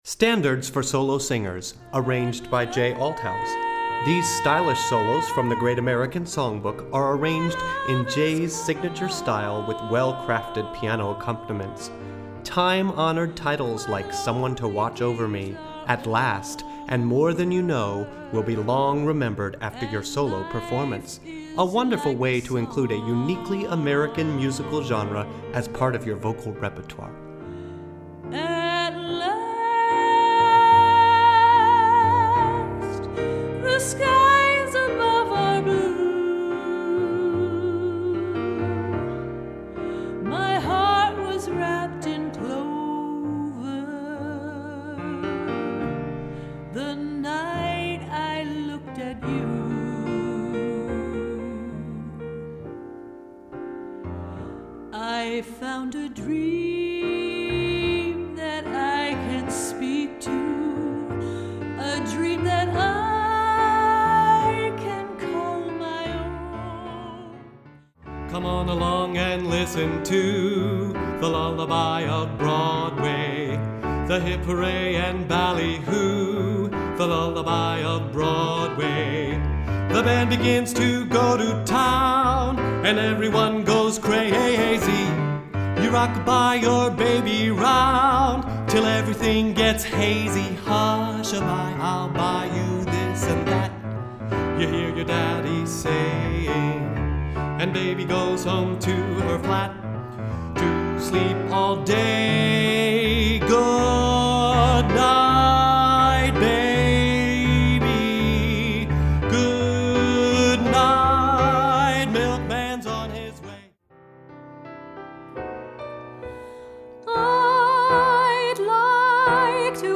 Voicing: Medium-High Voice w/